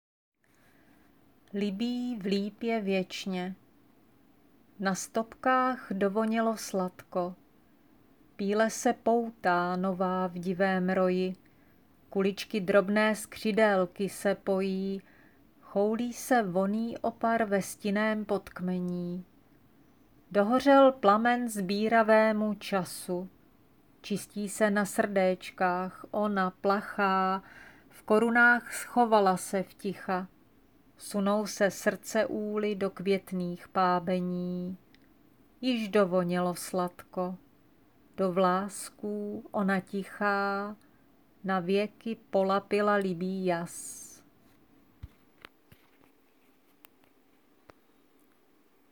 jsi konejšivá, vlídná...